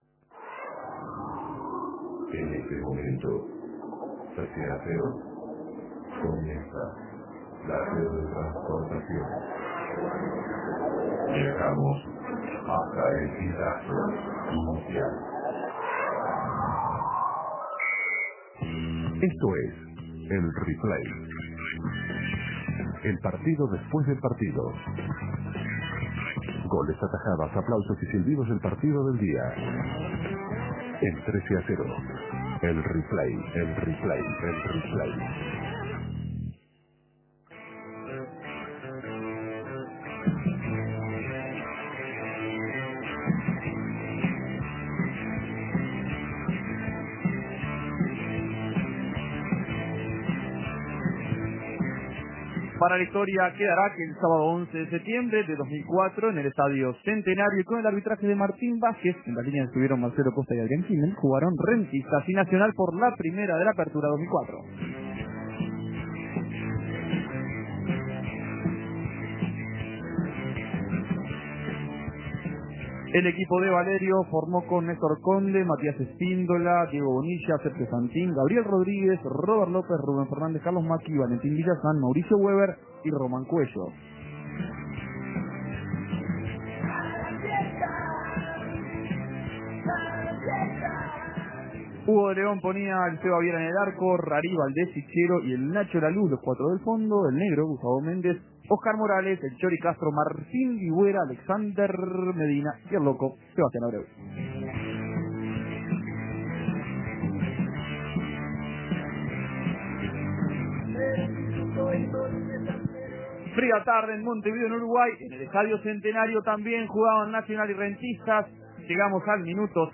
Goles y comentarios Replay: Nacional 1 - Rentistas 0 Imprimir A- A A+ Primera fecha del Apertura, primer partido de Nacional... primer gol del loco Abreu.